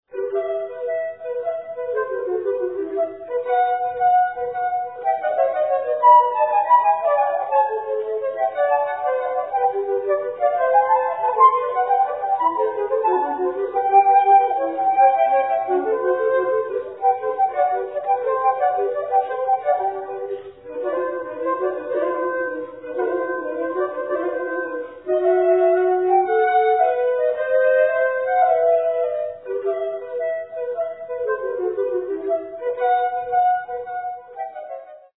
sonata for 2 flutes No. 4 in F minor
Presto - 2:21